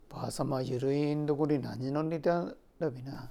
Aizu Dialect Database
Type: Single wh-question
Final intonation: Falling
WhP Intonation: Rising
Location: Showamura/昭和村
Sex: Male